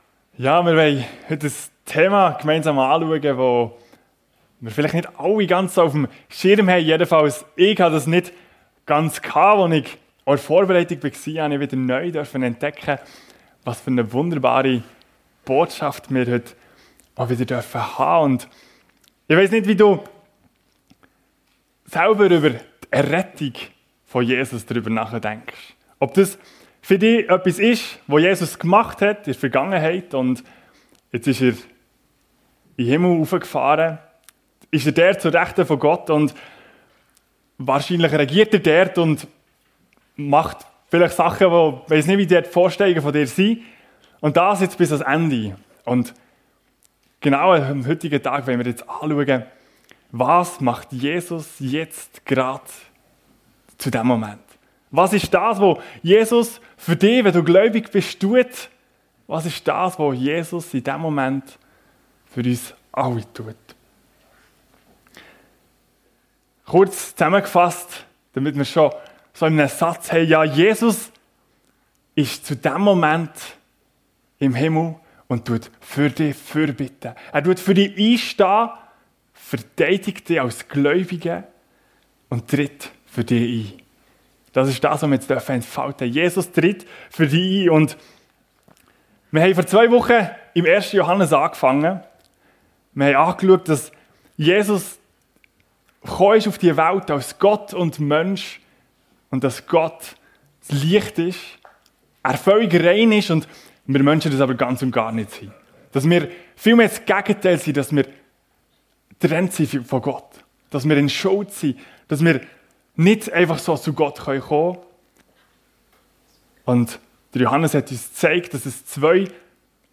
Jesus tritt für dich ein ~ FEG Sumiswald - Predigten Podcast